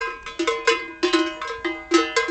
Kuhglocken klingelton kostenlos
Kategorien: Soundeffekte
kuhglocken.mp3